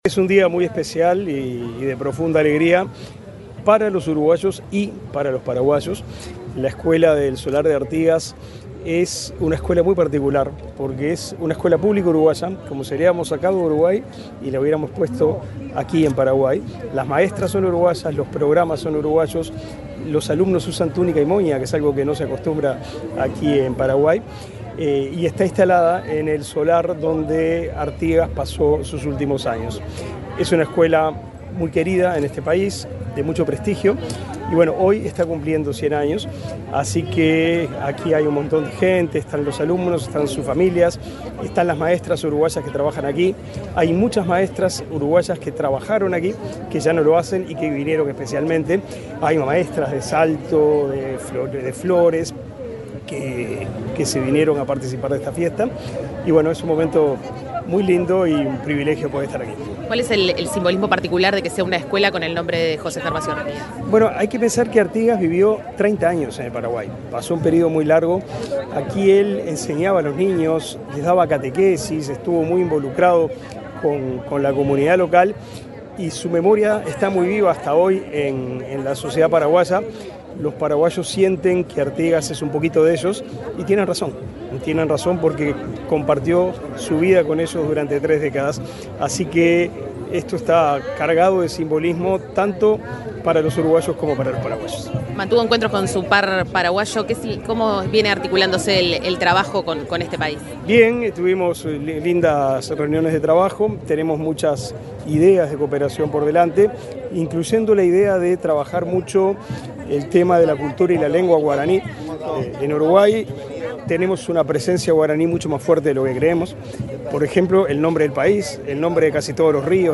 Entrevista al ministro Da Silveira en Paraguay